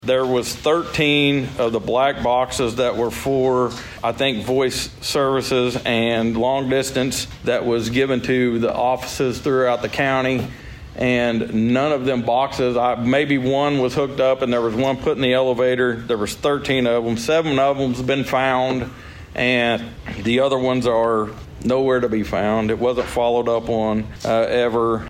District Two Commissioner Steve Talburt explains why they are ending that contract.